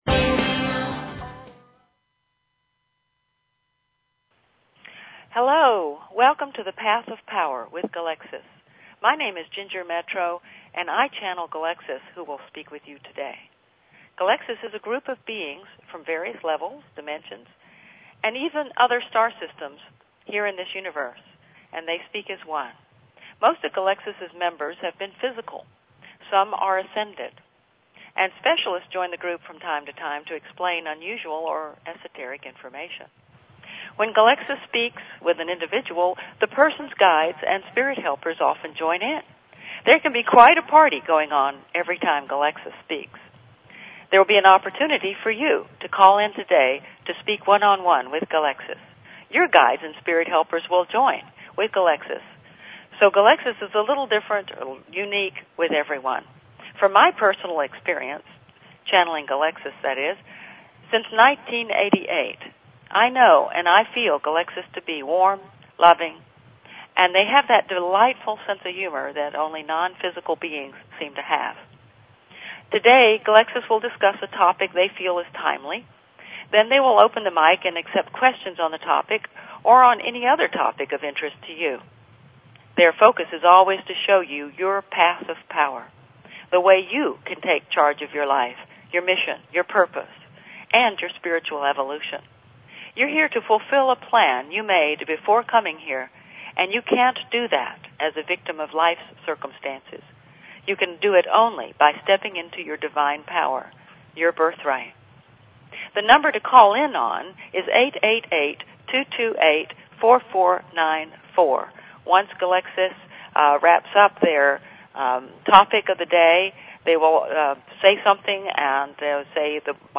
Talk Show Episode, Audio Podcast, Path_of_Power and Courtesy of BBS Radio on , show guests , about , categorized as